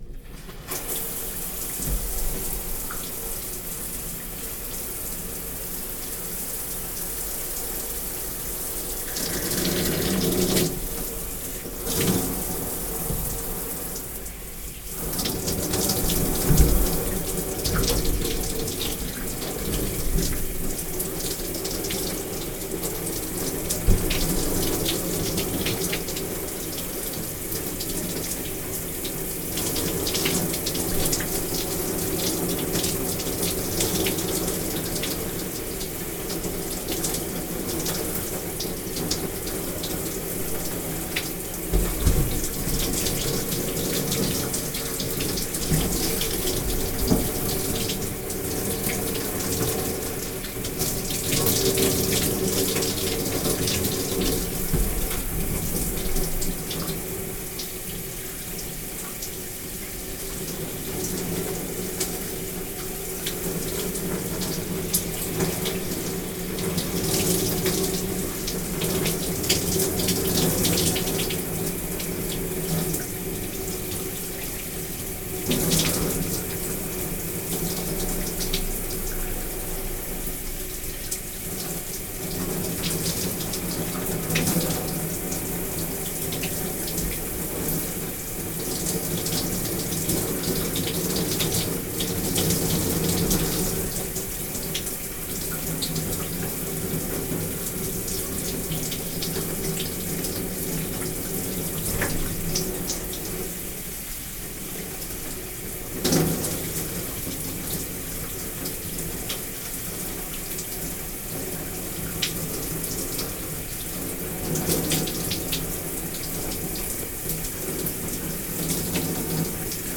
ユニットバスシャワー１
hotel_shower1.mp3